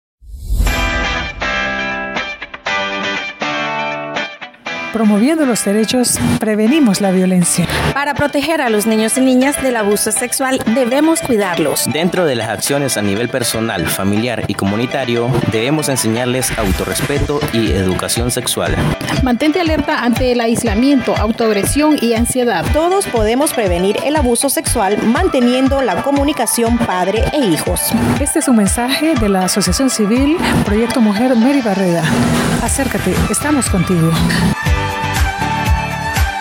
Como parte de la responsabilidad social compartida periodistas, comunicadoras y comunicadores sociales integrados en los procesos de formación que desarrolla la Asociación Mary Barreda sobre el abordaje de las Noticias con Enfoque de Derechos, elaboraron viñetas radiales en prevención de la violencia basada en género, abuso sexual y servicios que ofrece la organización.